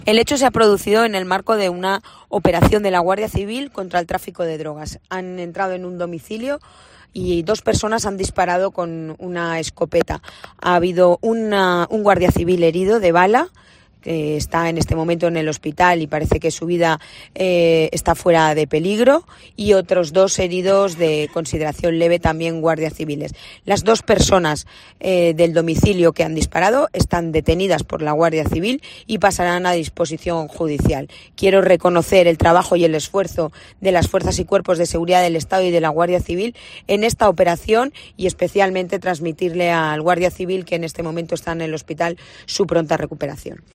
Pilar Bernabé, delegada del Gobierno: La vida del guardia civil herido no corre peligro